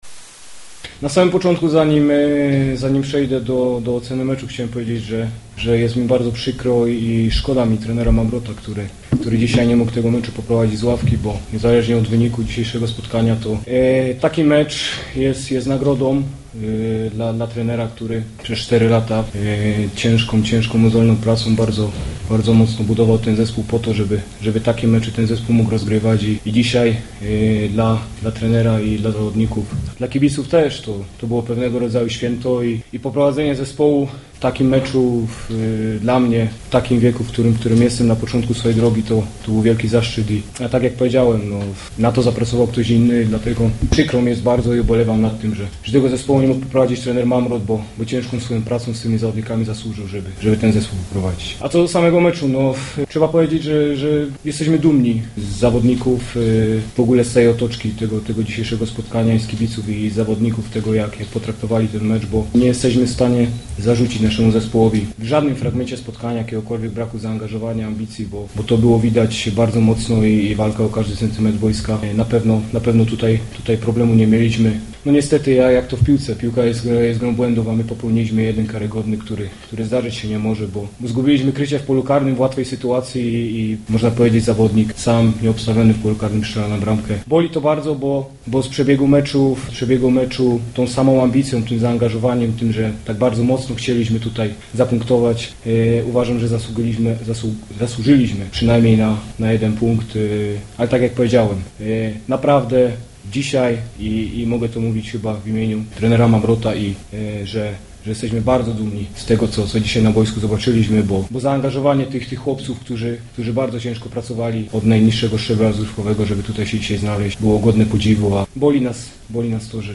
Pomeczowa konferencja prasowa